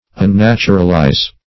Unnaturalize \Un*nat"u*ral*ize\
unnaturalize.mp3